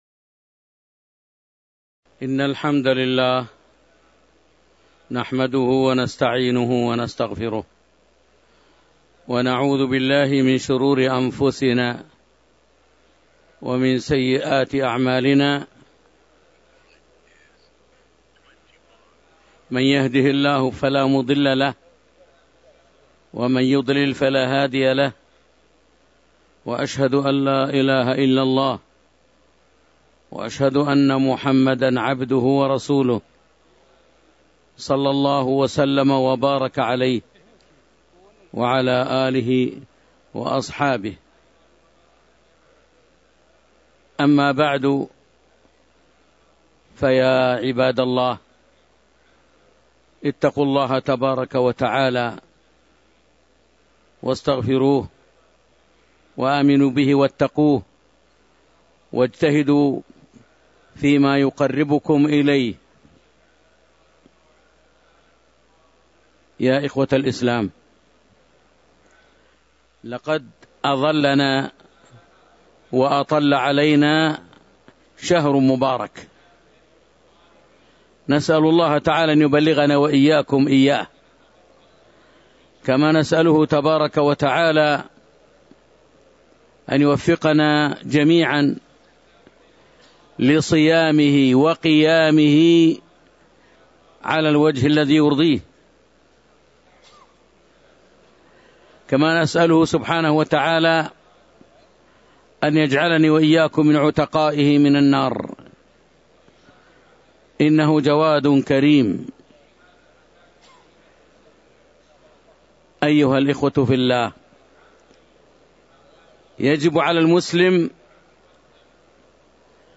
تاريخ النشر ٢٩ شعبان ١٤٤٣ هـ المكان: المسجد النبوي الشيخ